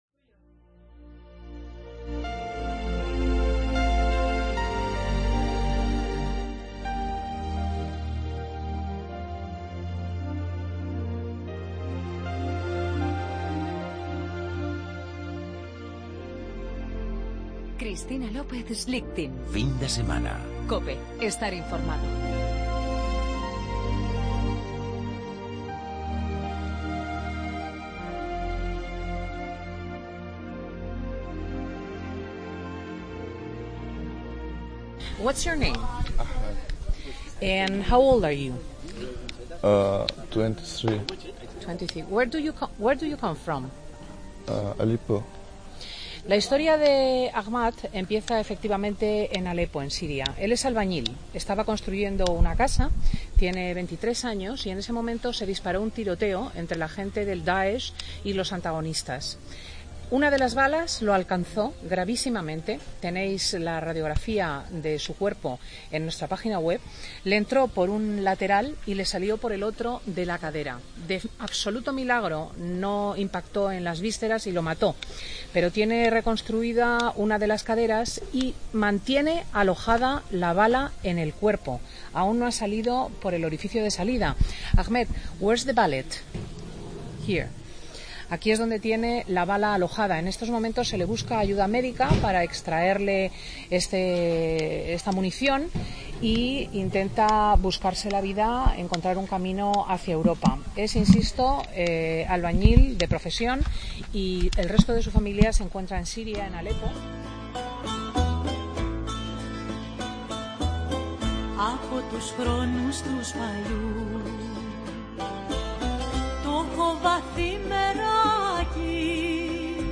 Hacemos el programa desde Lesbos. Te contamos todos los testimonios de vida de los refugiados y héroes voluntarios que se encuentran en los diferentes campamentos de Lesbos, en Grecia. Además cubrimos la visita especial del Papa Francisco a la isla. Asimismo hablamos con Cayetano Martínez de Irujo sobre su compromiso con los refugiados.